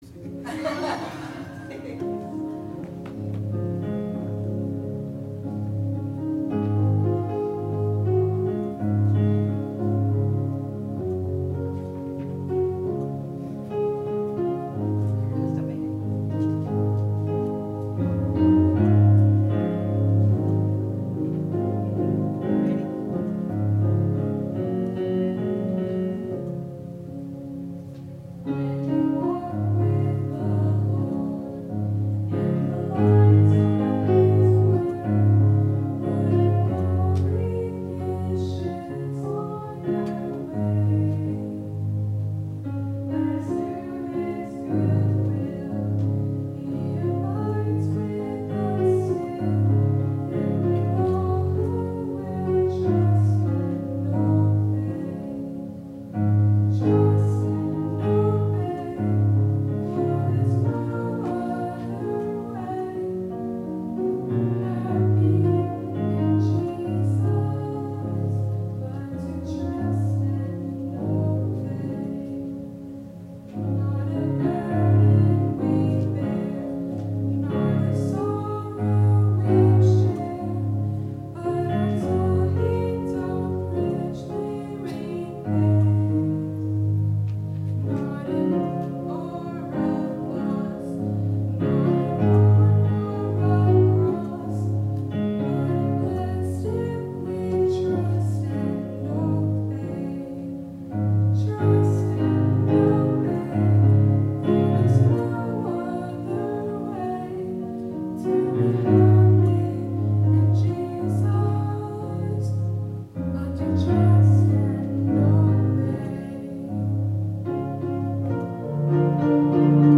Autaugaville Baptist Church Sermons